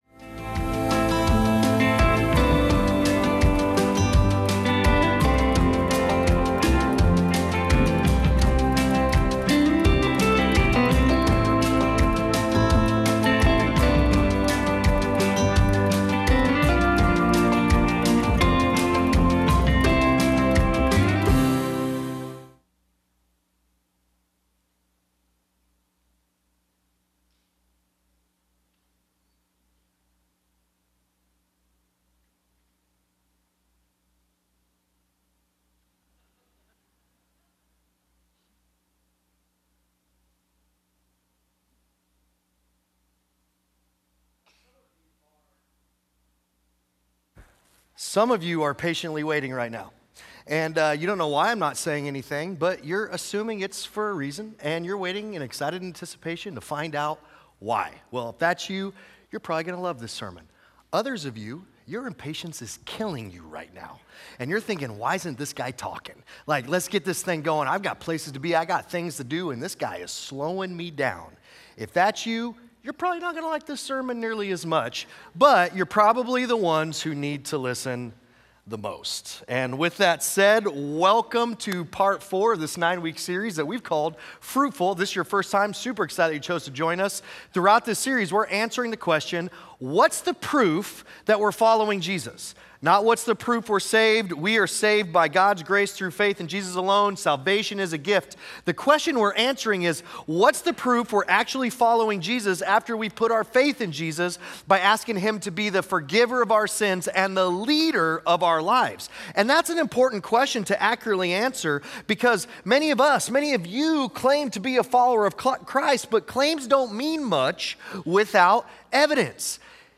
Sunday Sermons FruitFULL, Week 4: "Patience" Feb 16 2026 | 00:37:52 1x 00:00 / 00:37:52 Subscribe Share Apple Podcasts Spotify Overcast RSS Feed Share Link Embed